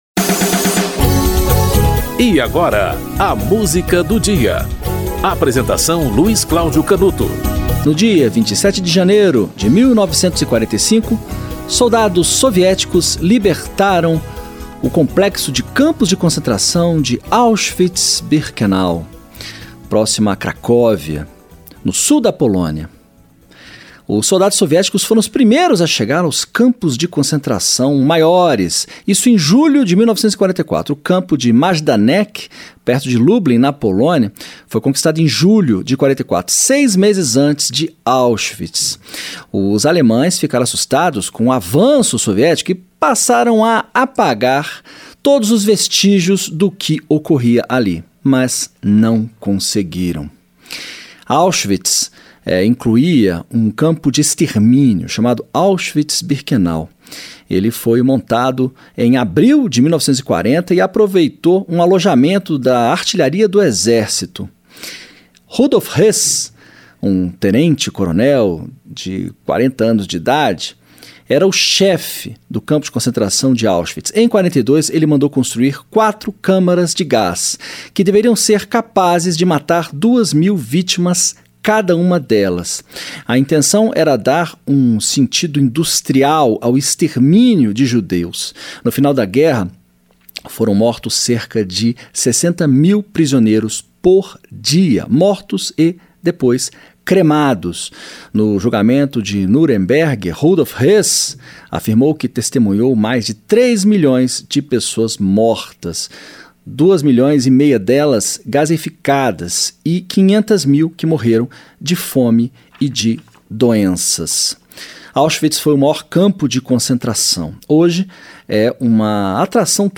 Orquestra Estadual de Hamburgo - Peça para quarteto de cordas número 3 (Viktor Ullmann)